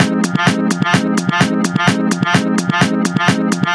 VEH1 Fx Loops 128 BPM
VEH1 FX Loop - 25.wav